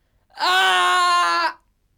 WoundedCry
Category: Sound FX   Right: Personal